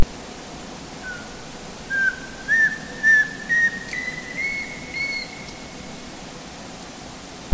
Der markante, hell pfeifende Revierruf des Männchens ist über einen Kilometer weit zu hören. Vor allem im Herbst wird der Gesang auch durch die sogenannte Tonleiter, eine aufsteigende Reihe von Tönen, ersetzt.
Audiodatei Sperlingskauz Revierruf im Herbst (Tonleiter)
Sperlingskauz_Revierruf_im_Herbst_Tonleiter.mp3